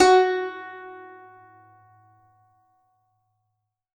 Index of /90_sSampleCDs/Best Service ProSamples vol.52 - World Instruments 2 [AIFF, EXS24, HALion, WAV] 1CD/PS-52 WAV WORLD INSTR 2/STRINGED INSTRUMENTS/PS ZHENG HARP
52-str12-zeng-f#3.wav